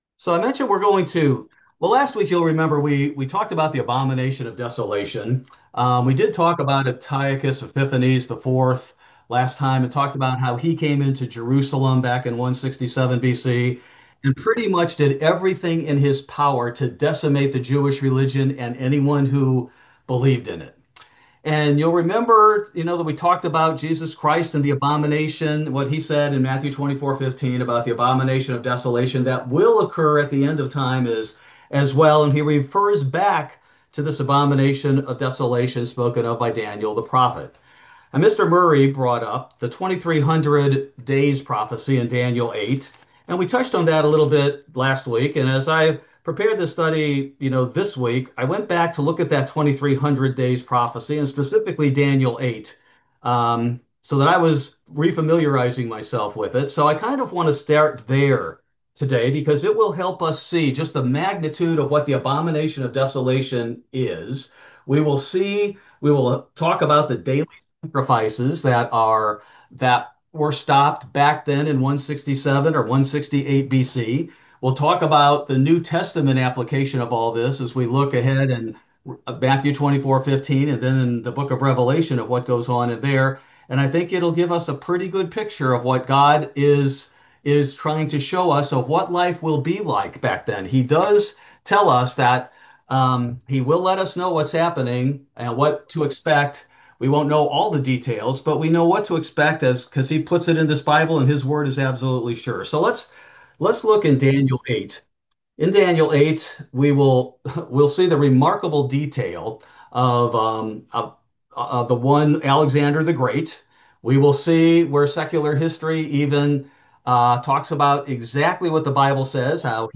Bible Study: May 21, 2025